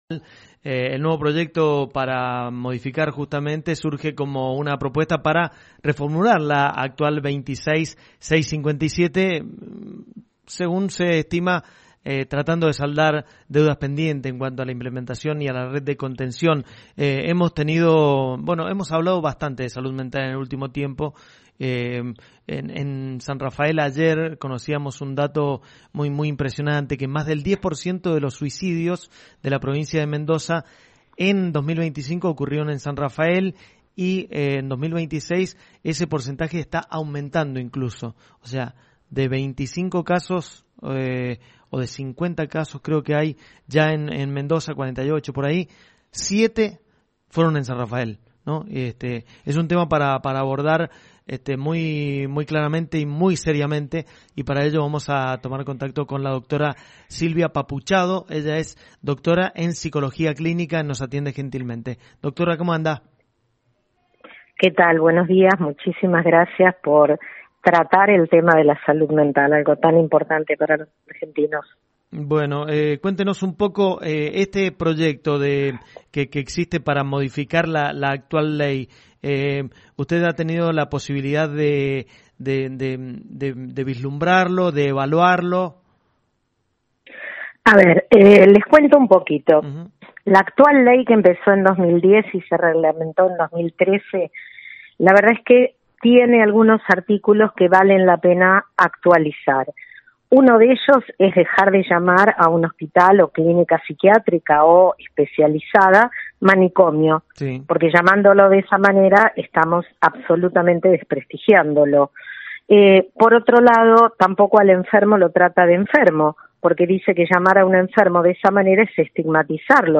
En diálogo con LV18